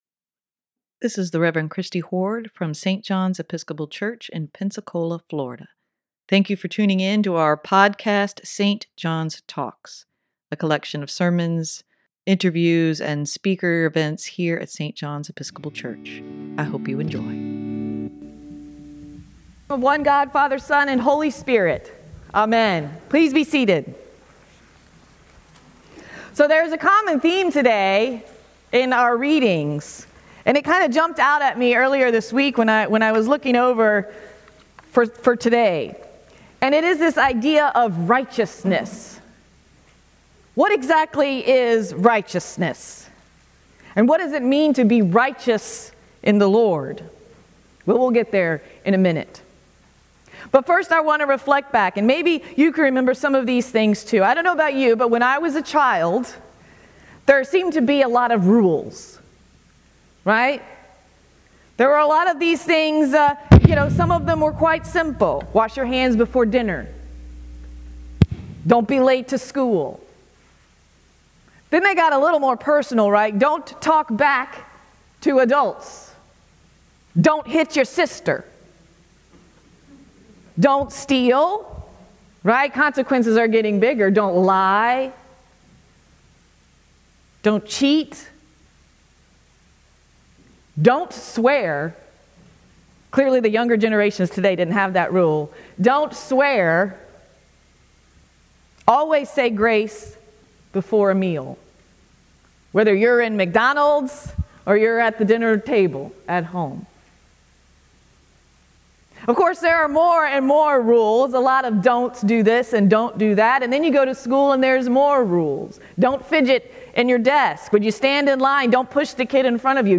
sermon-2-5-23.mp3